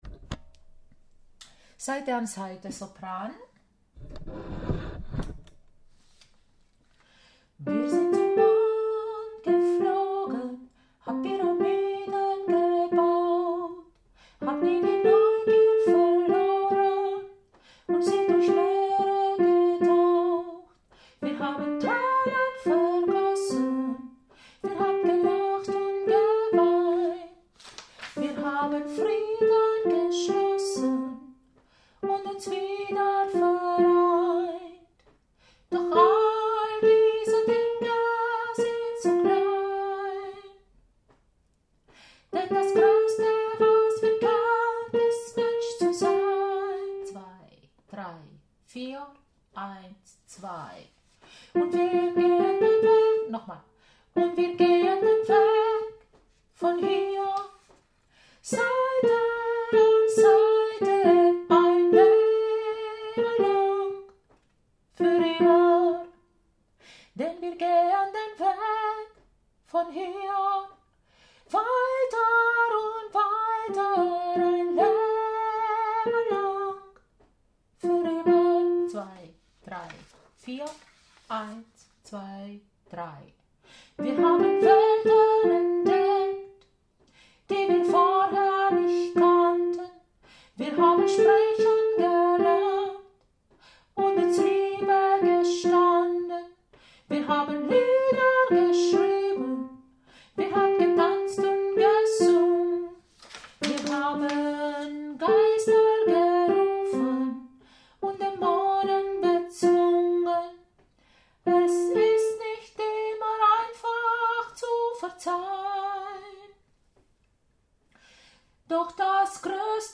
02 - Sopran - ChorArt zwanzigelf - Page 7